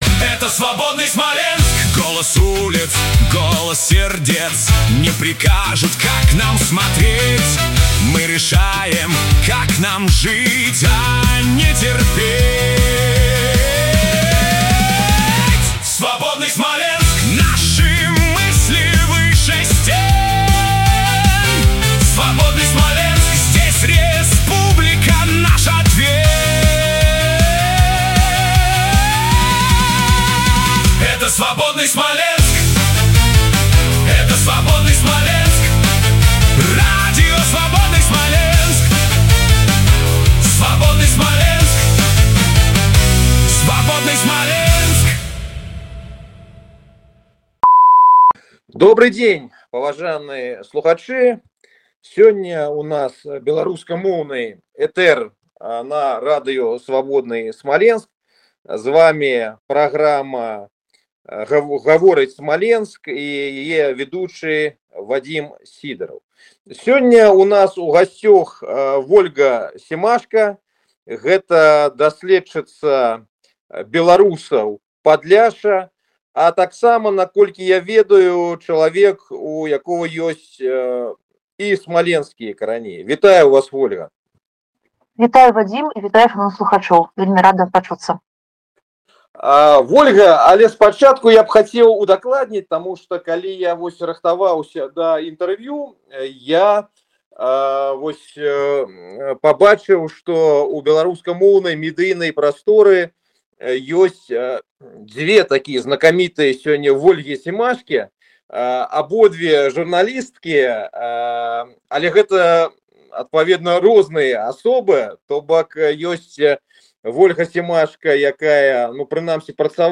У гутарцы з вядучым